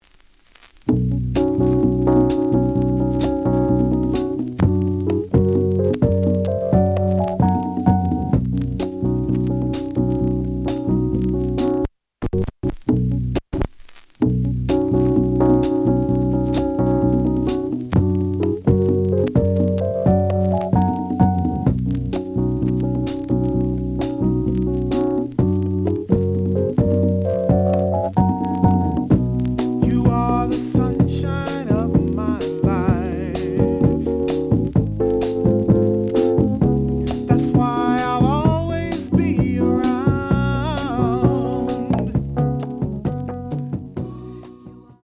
The audio sample is recorded from the actual item.
●Genre: Soul, 70's Soul
Some noise on A side.